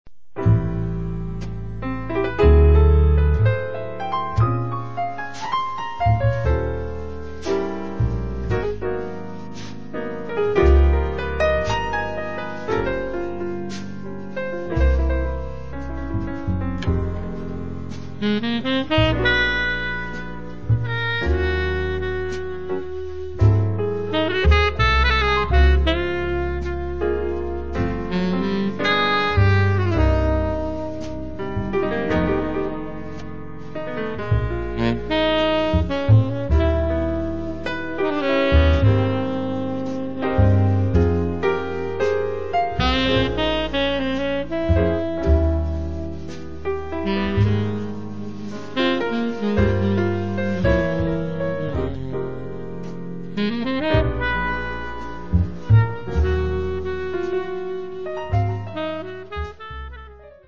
piano, electric piano
soprano saxophone, tenor saxophone
acoustic bass
drums